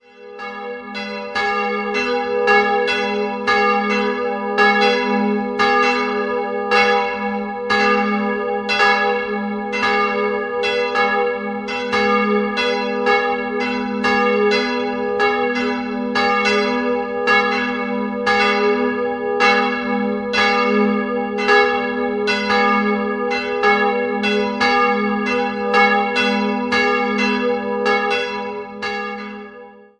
Geyern, Evang. Bartholomäuskirche (ehem. Schlosskirche) Im Dorf Geyern nordöstlich von Weißenburg war einst ein mächtiges Adelsgeschlecht ansässig. Die kleine Bartholomäuskirche stammt im Kern noch aus spätgotischer Zeit, wurde in der Barockzeit jedoch ausgebaut. 2-stimmiges Geläut: gis'-h' D ie beiden Eisenhartgussglocken wurden nach dem Zweiten Weltkrieg von Ulrich&Weule in Bockenem gegossen.